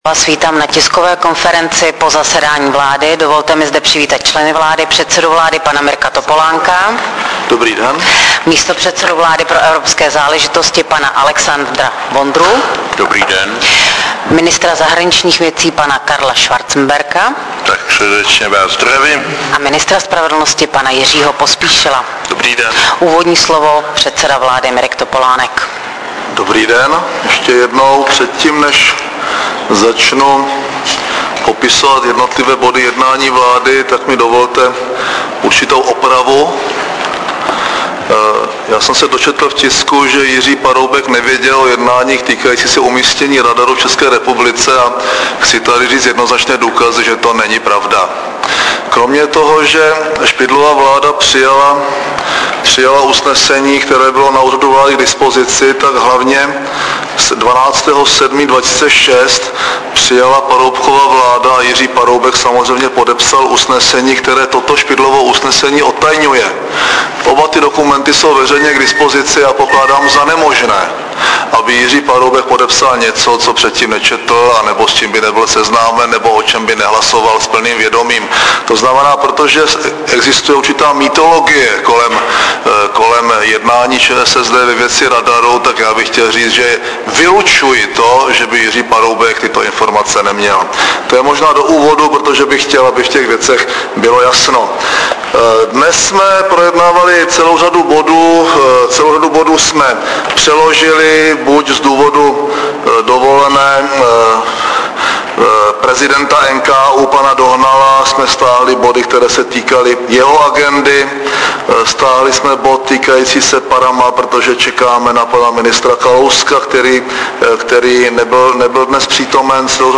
Tisková konference po jednání vlády ČR 27. srpna 2008